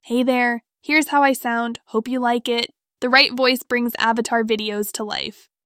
Spirited Sophie - Excited 🤩
🌍 Multilingual👩 Женский
Пол: female
Этот скрипт отображает тайские голоса и голоса с поддержкой множественных языков из HeyGen API с возможностью фильтрации.